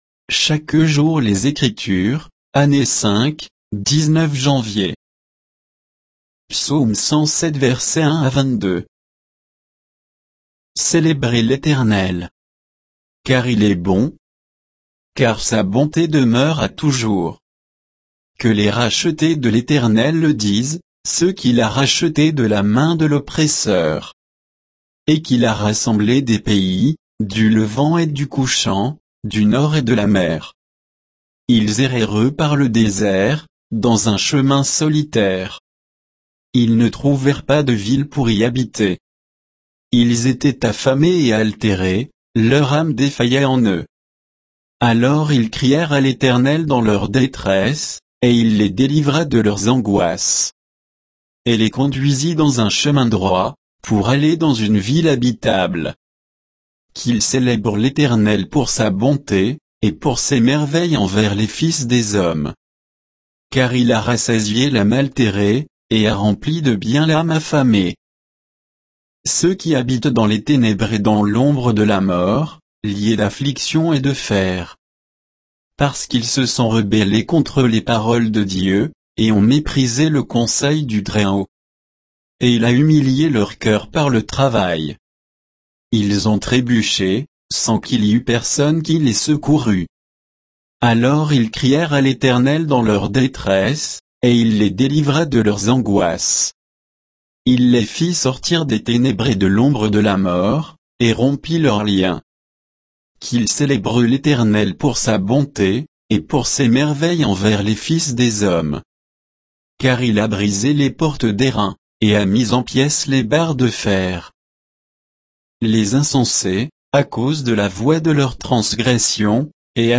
Méditation quoditienne de Chaque jour les Écritures sur Psaume 107, 1 à 22